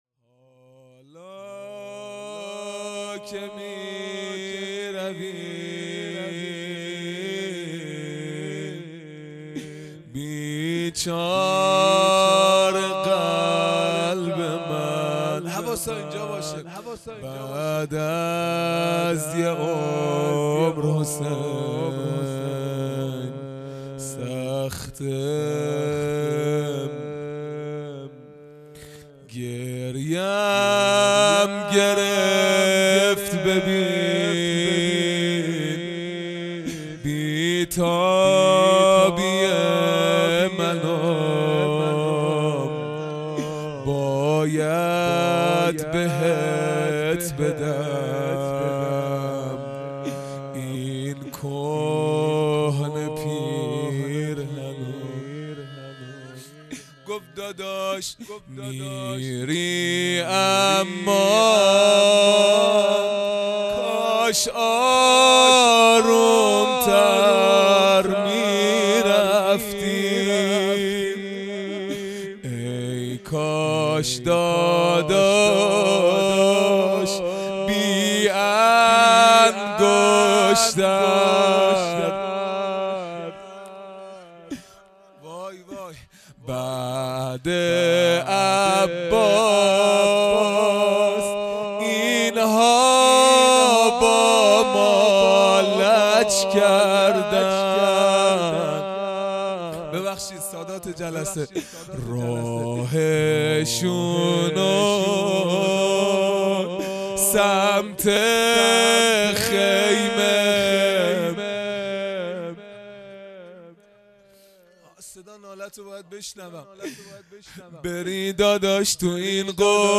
خیمه گاه - هیئت بچه های فاطمه (س) - روضه | حالا که میروی